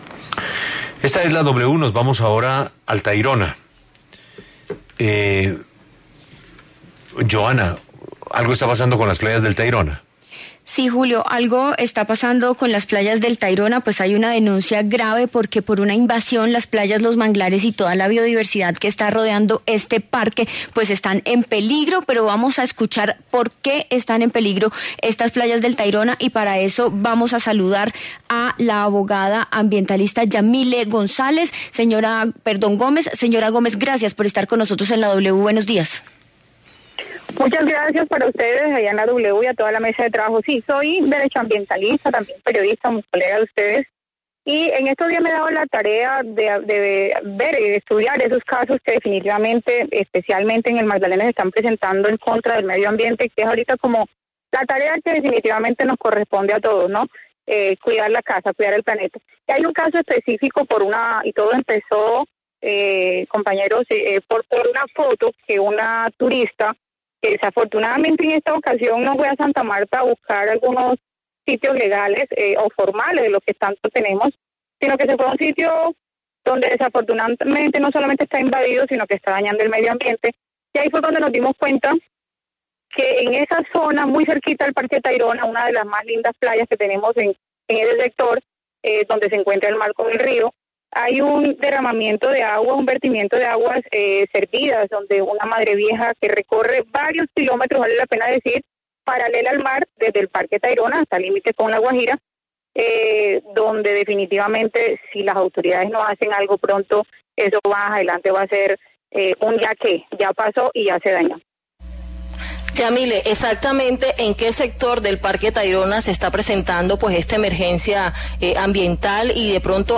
se pronunció en La W sobre el vertimiento de desechos y aguas servidas que recorre por varios kilómetros la paralela al mar desde el Parque Tayrona.